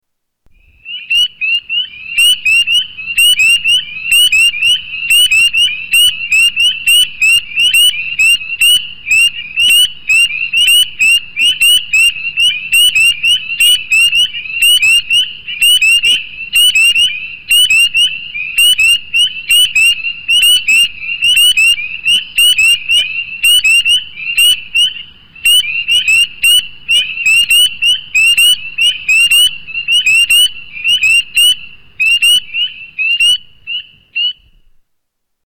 Spring Peeper